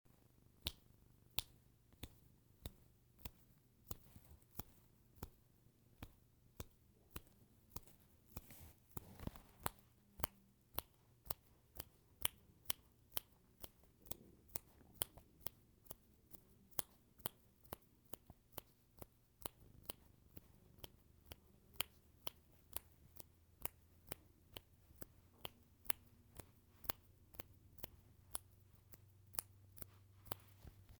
field recording 4
sound heard is myself attempting to snap
Myself-Snapping1.mp3